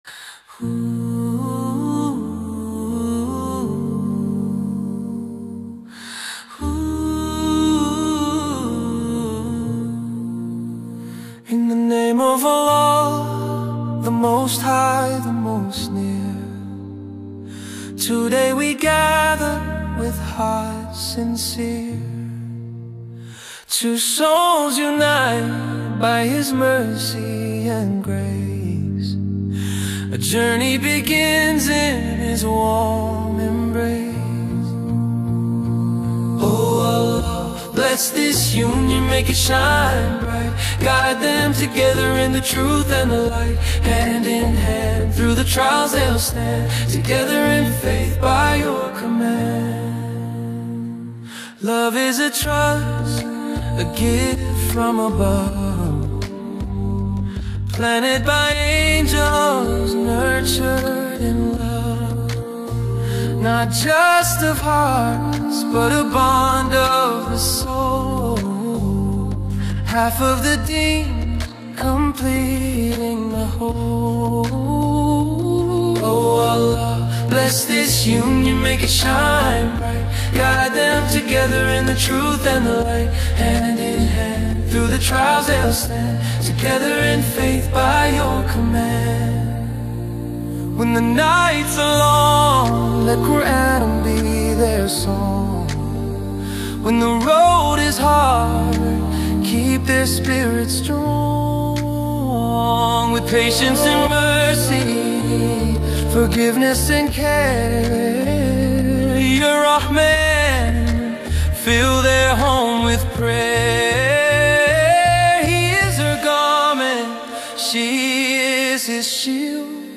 🎤 Style: Vocals-only Nasheed (No Music)
🌙 Genre: Islamic Wedding / Nikah Nasheed